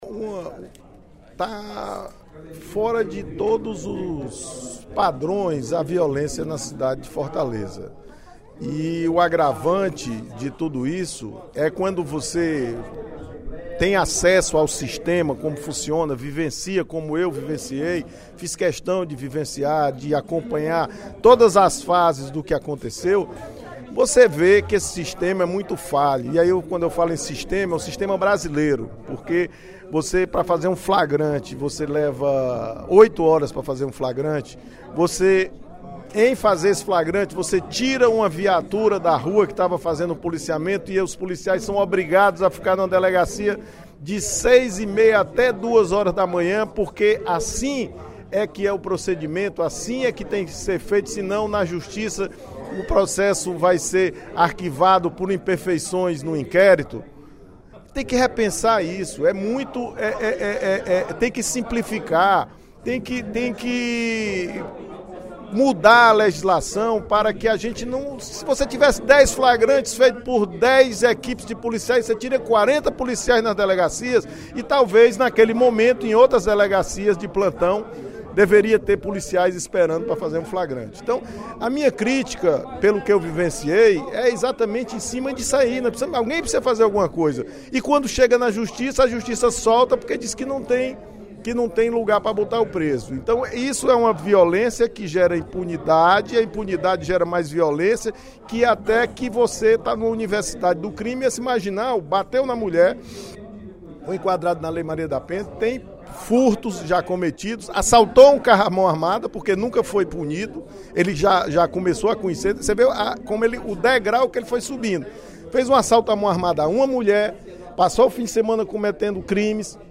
O deputado João Jaime (DEM) criticou, durante o primeiro expediente da sessão plenária desta terça-feira (15/12), a burocracia encontrada nos procedimentos de apuração de crimes no País.